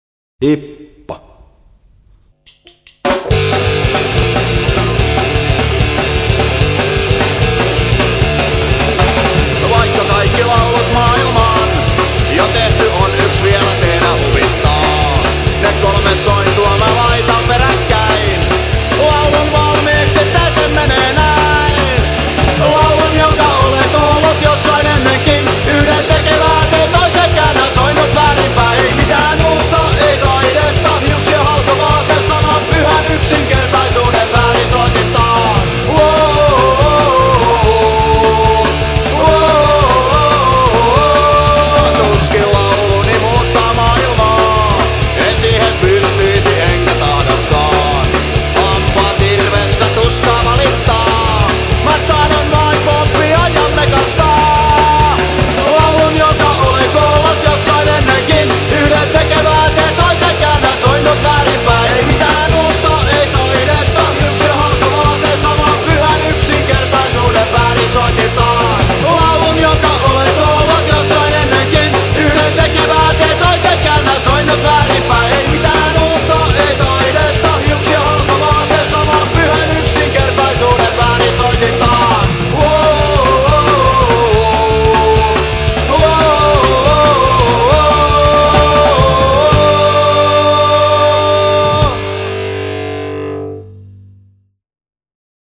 Обалденная панк группа!